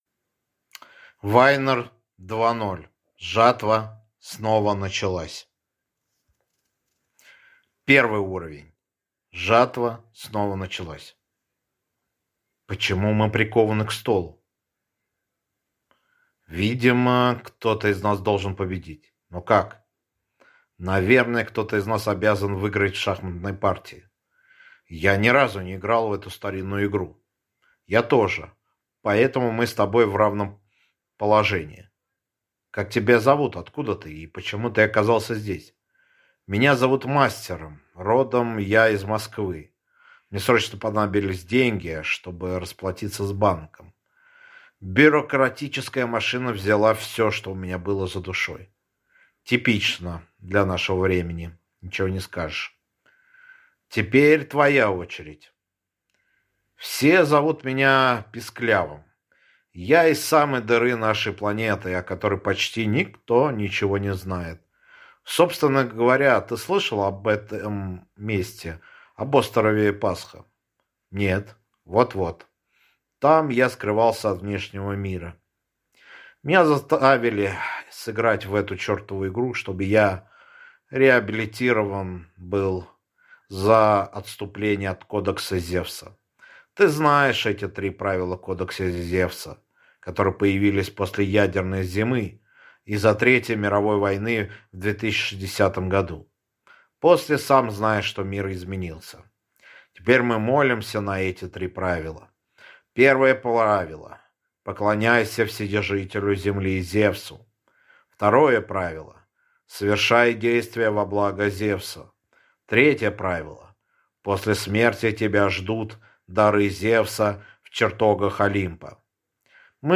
Аудиокнига Вайнар 2.0: Жатва снова началась | Библиотека аудиокниг